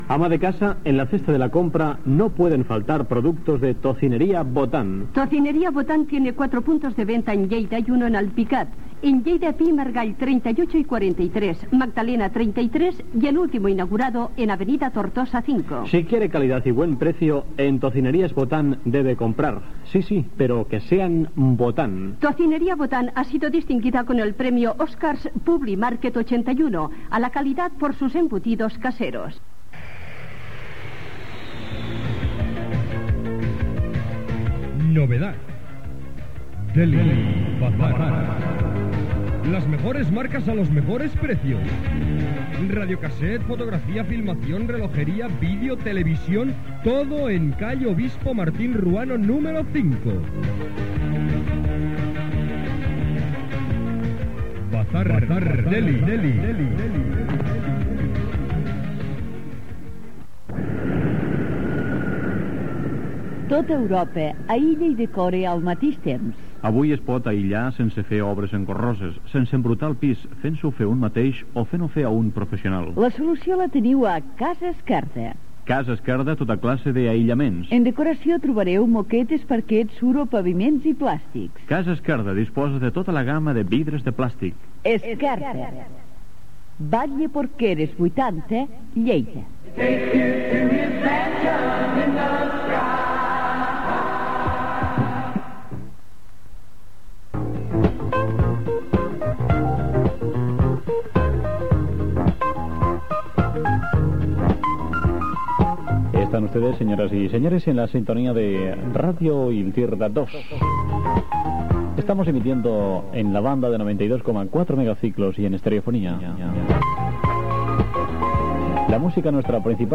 Publicitat, identificació, connexió amb la Cadena Catalana.
FM